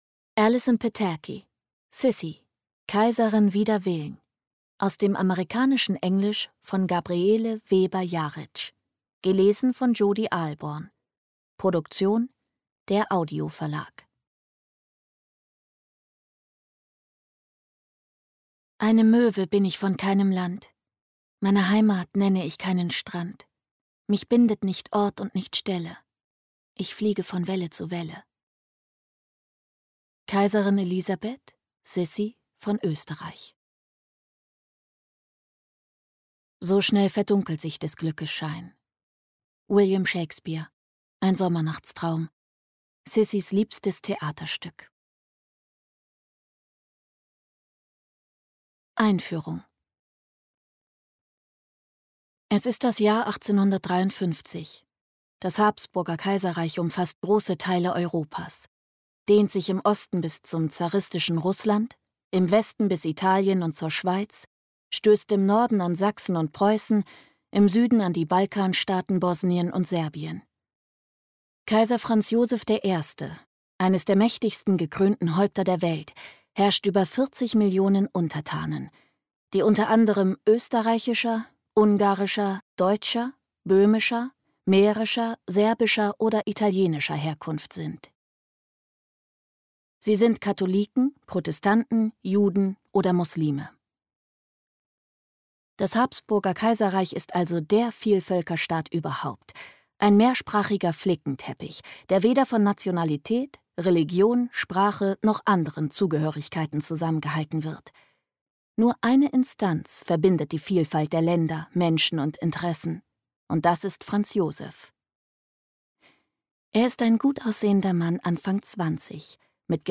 2022 | Gekürzte Lesung